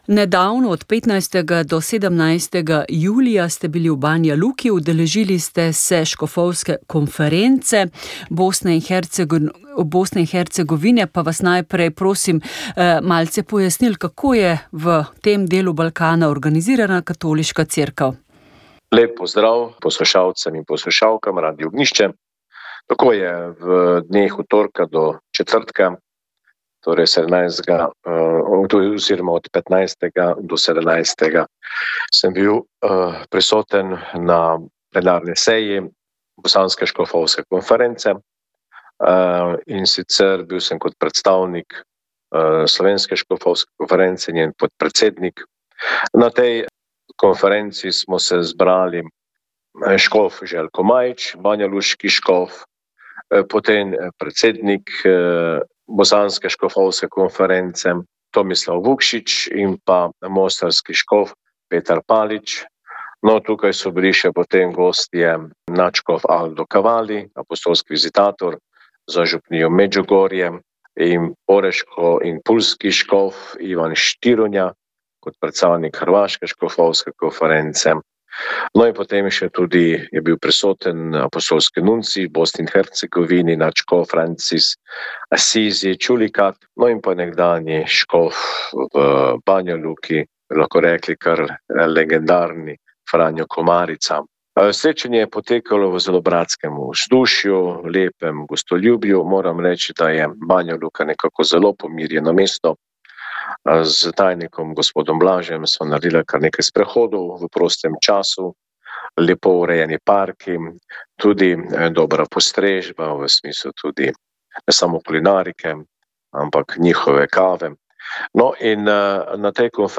O vlogi papeža sv. Janeza Pavla II., o pomenu takšnega priznanja za neko državo in katere sporazume podpiše Sveti sedež s posamezno državo smo se pogovarjali z nadškofom Antonom Stresom.